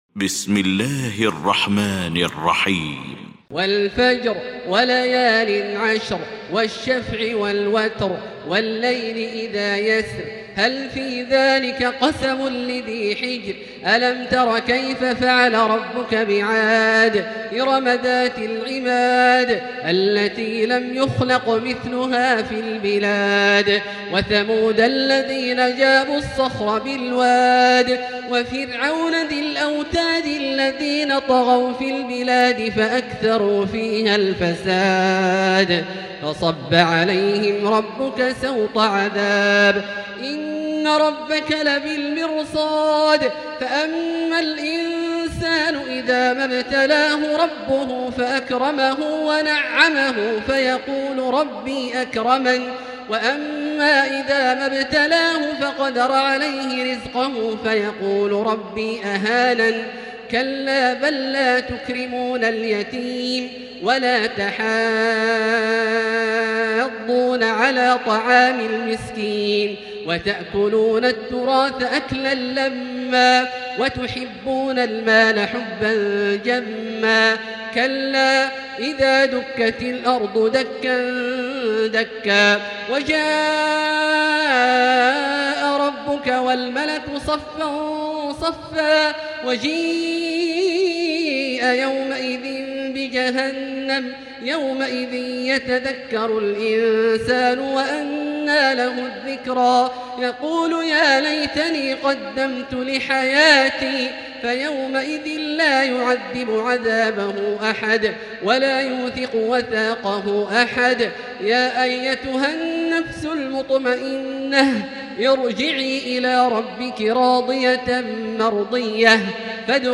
المكان: المسجد الحرام الشيخ: فضيلة الشيخ عبدالله الجهني فضيلة الشيخ عبدالله الجهني الفجر